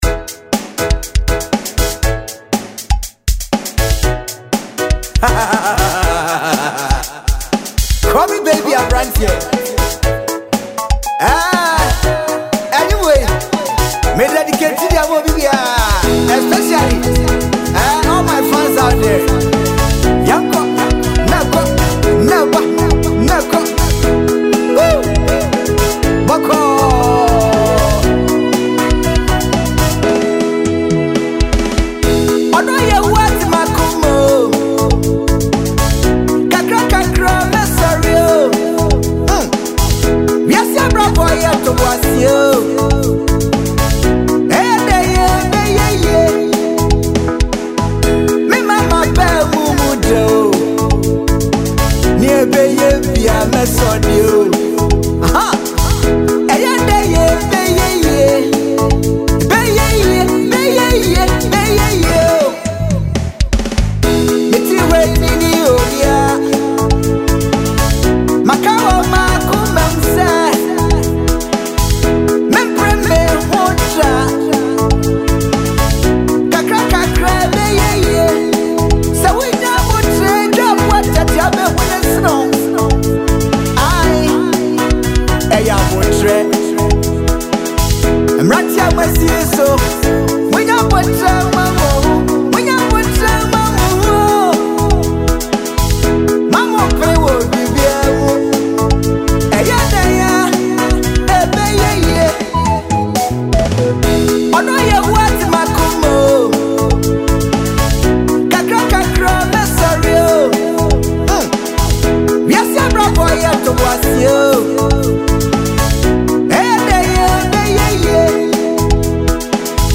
smooth yet powerful production